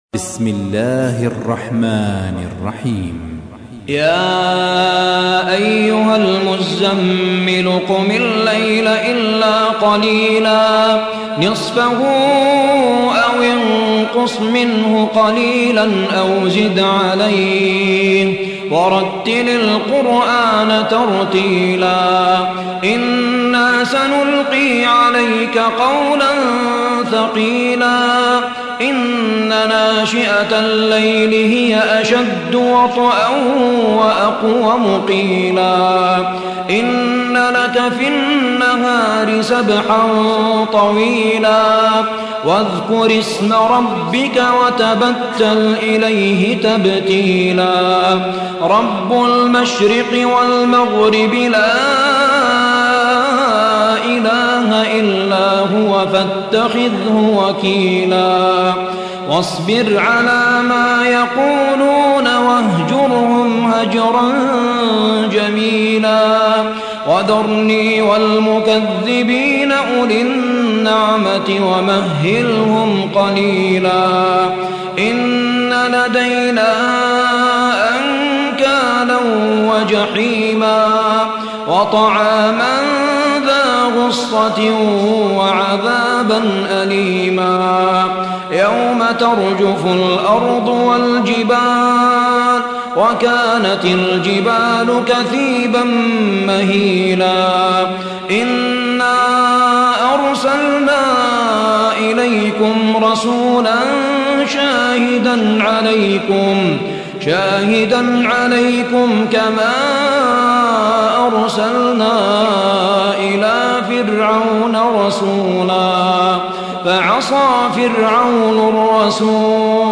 73. سورة المزمل / القارئ